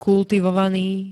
kultivovaný [-t-] -ná -né 2. st. -nejší príd.
Zvukové nahrávky niektorých slov